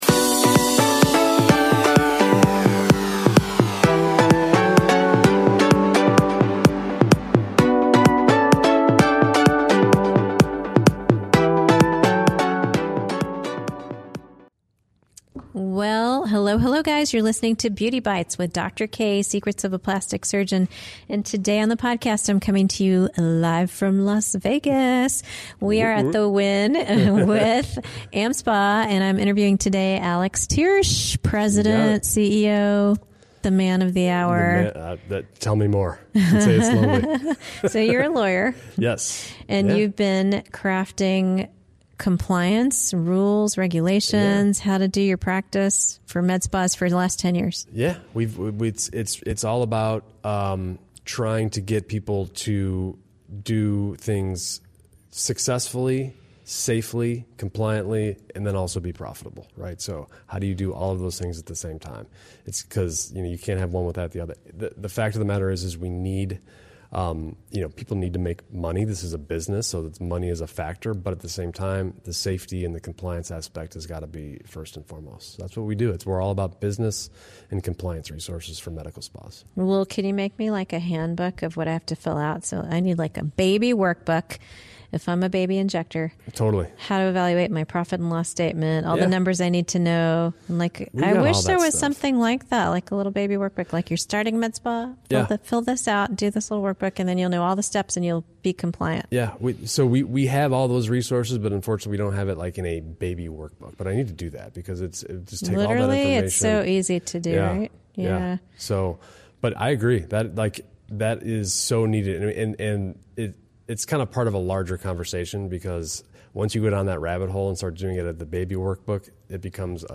Coming from you, live at the Wynn in Las Vegas!!